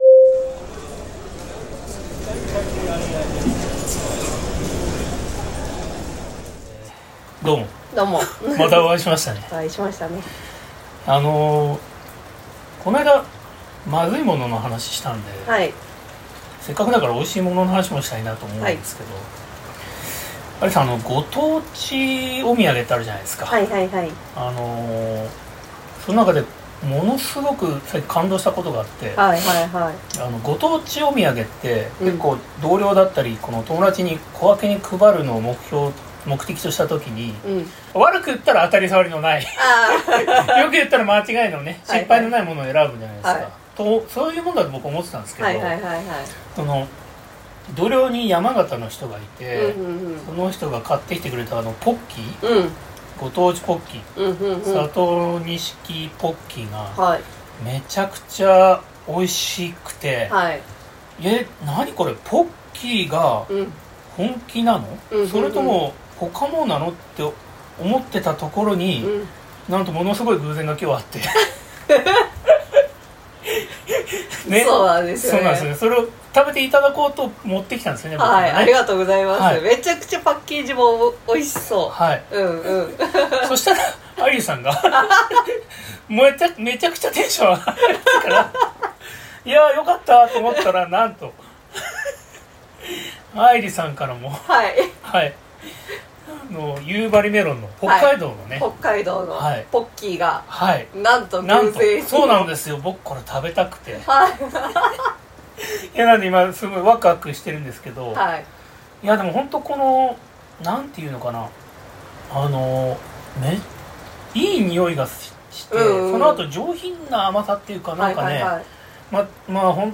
今日二人は何やら「美味しかったもの」の話をしているようです。
Audio Channels: 2 (stereo)